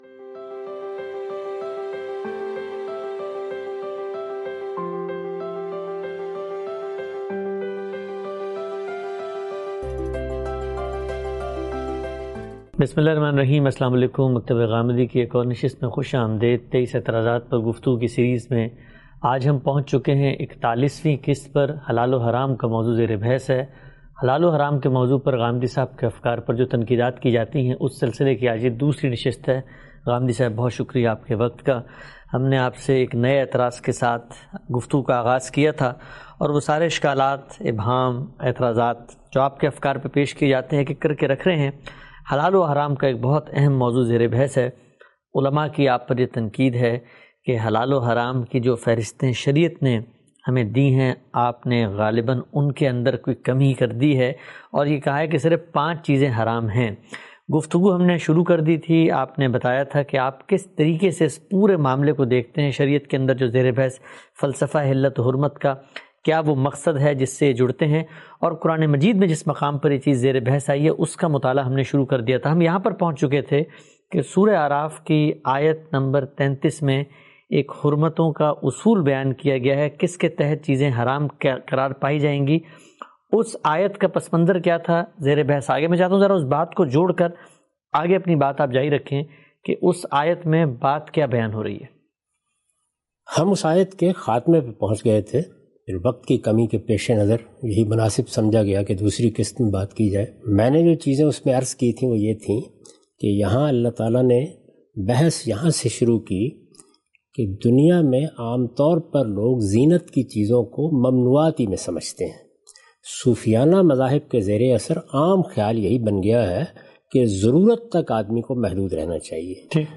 In this video, Mr Ghamidi answers questions